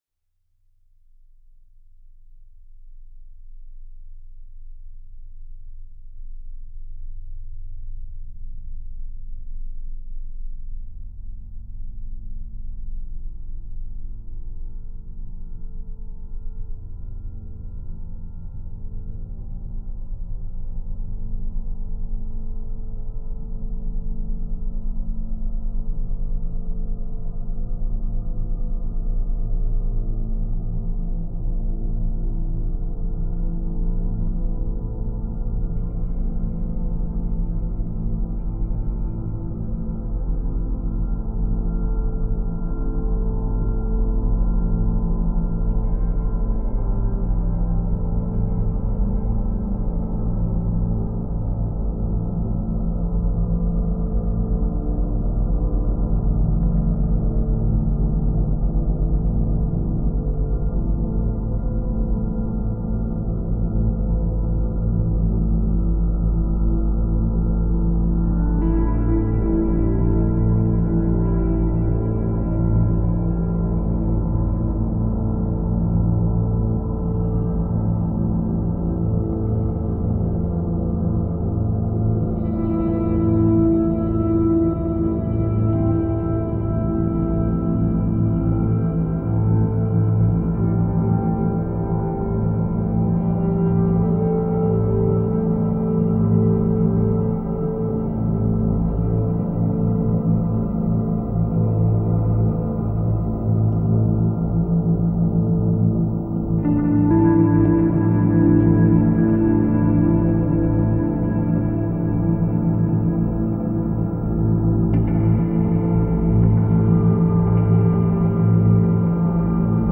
Drone / Ambient